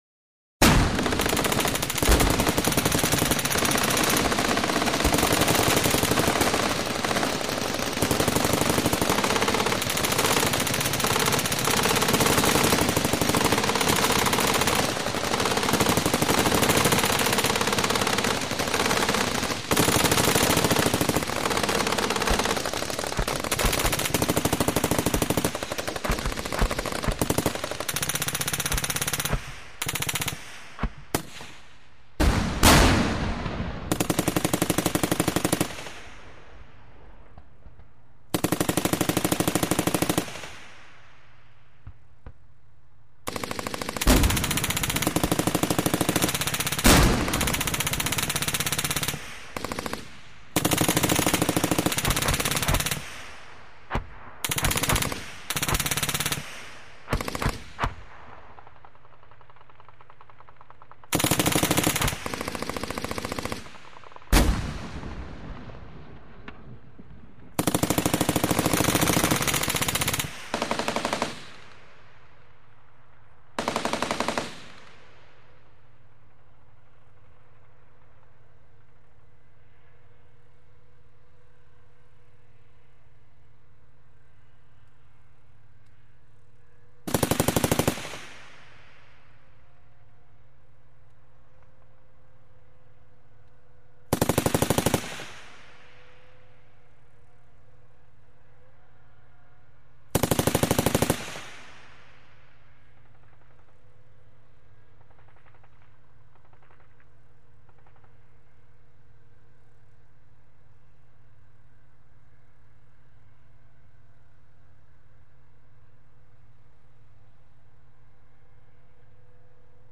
SFX 战争激烈战场音效下载
SFX音效